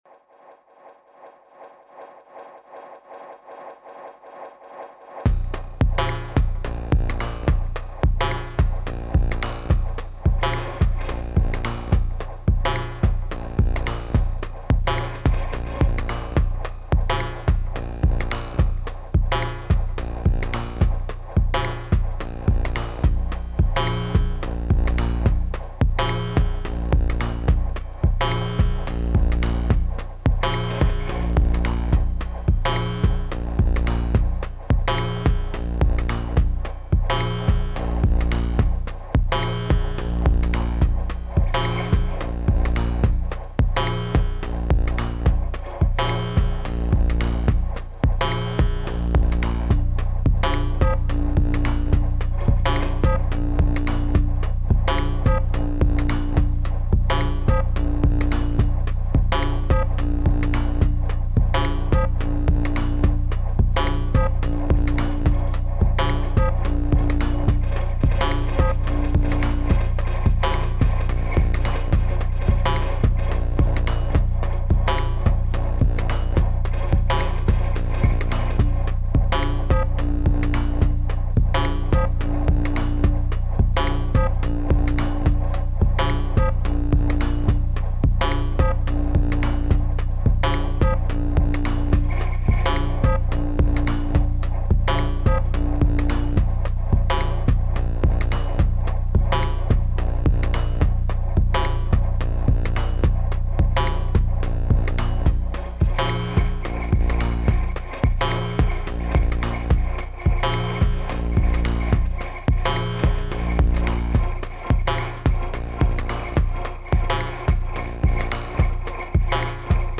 Kept it simple today. Couple of Volca kick & drum loops with some guitar scratches on top.